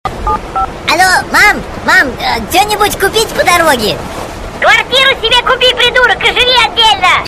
Другие рингтоны по запросу: | Теги: Анекдот
Категория: Смешные реалтоны